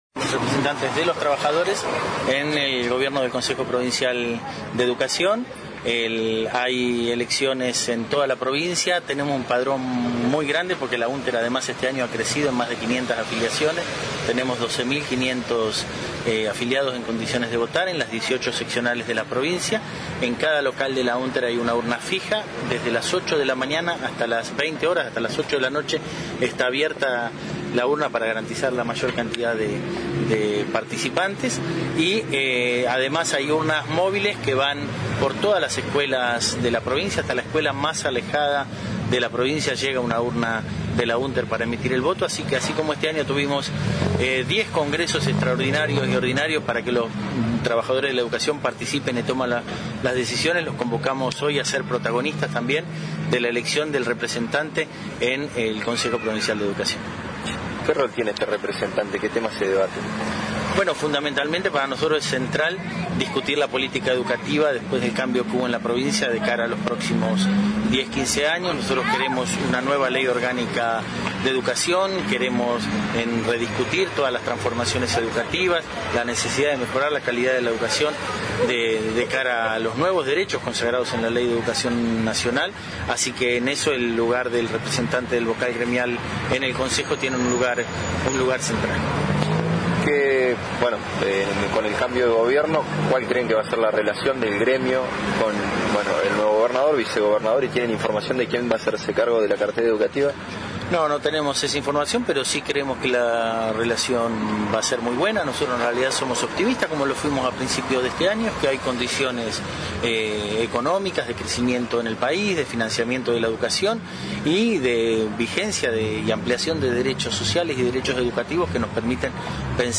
en una entrevista realizada por el noticiero regional Somos el Valle